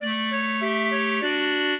clarinet
minuet3-8.wav